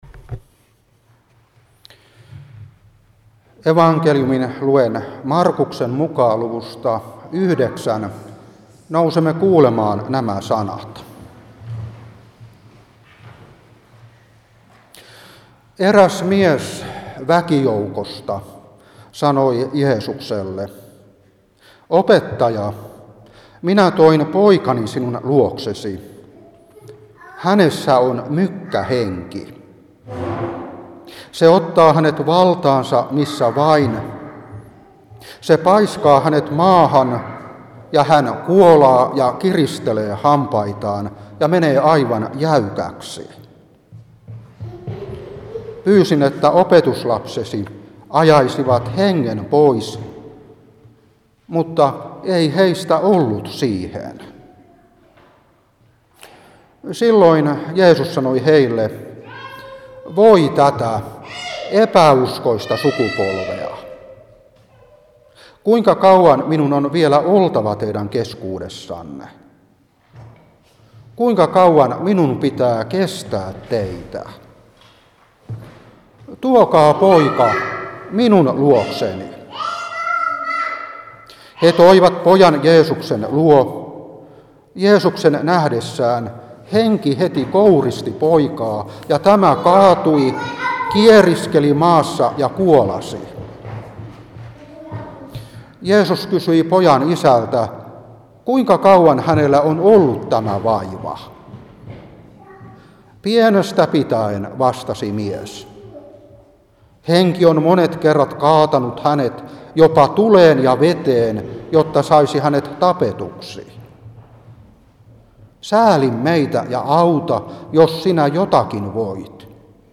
Saarna 2023-3. Mark.9:17-29.